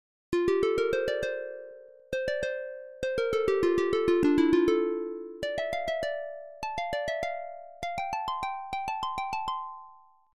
Tag: 100 bpm Classical Loops Guitar Electric Loops 1.73 MB wav Key : Unknown